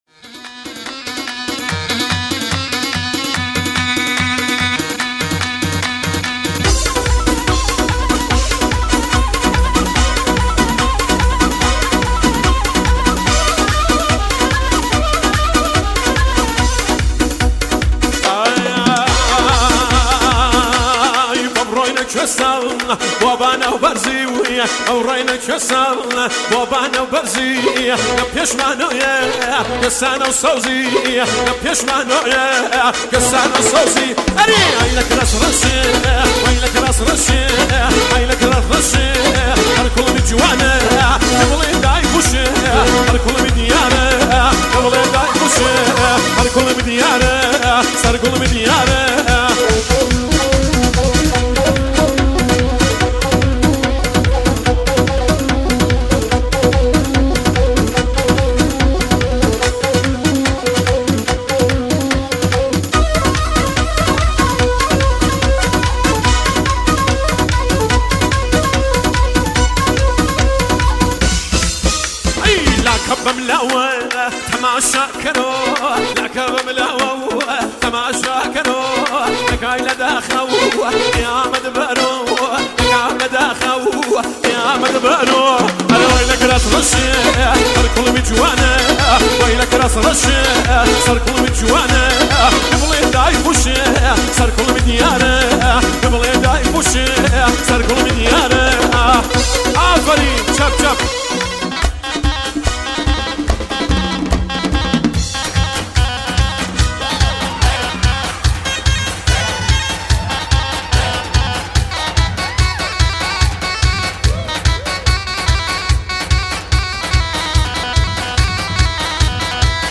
آهنگ کردی فولکلور